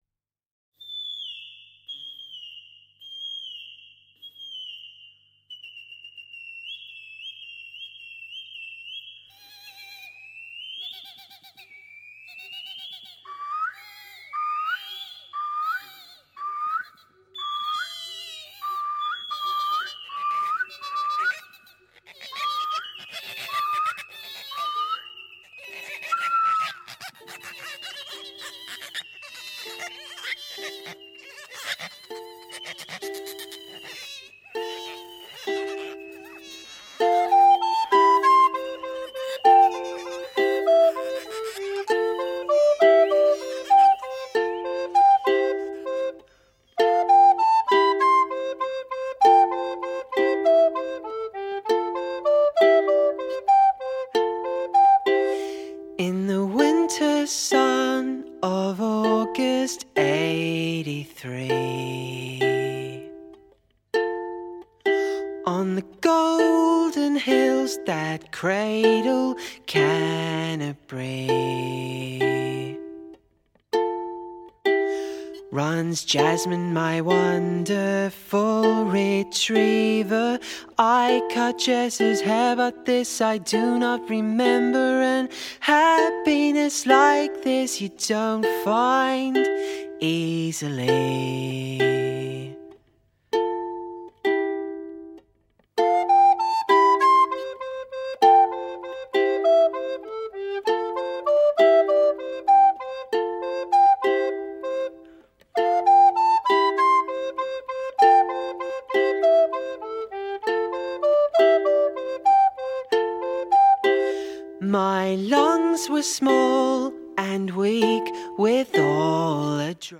クラシカルなチェンバー・ポップ！